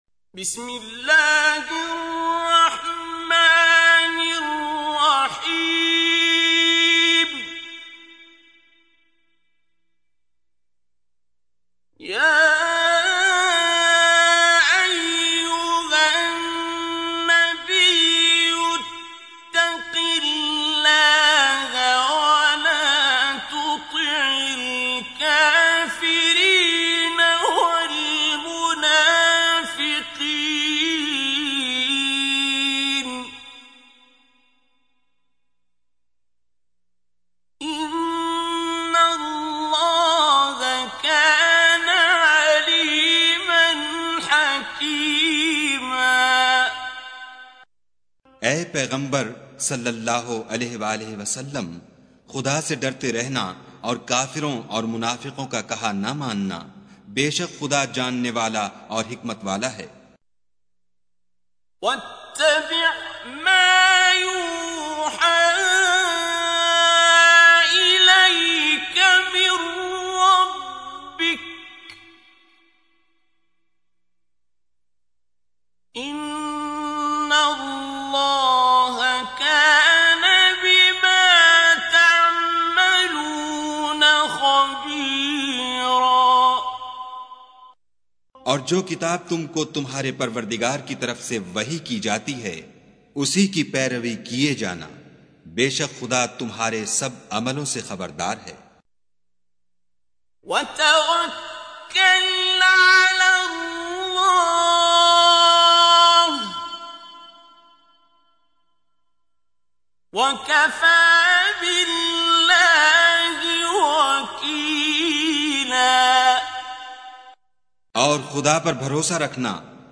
Surah Repeating تكرار السورة Download Surah حمّل السورة Reciting Mutarjamah Translation Audio for 33. Surah Al�Ahz�b سورة الأحزاب N.B *Surah Includes Al-Basmalah Reciters Sequents تتابع التلاوات Reciters Repeats تكرار التلاوات